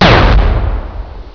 Laser1
LASER1.WAV